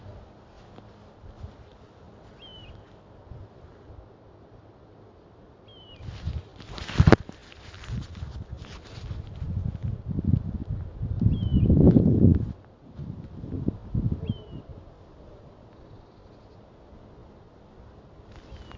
золотистая ржанка, Pluvialis apricaria
Ziņotāja saglabāts vietas nosaukumsVecdaugava
СтатусСлышен голос, крики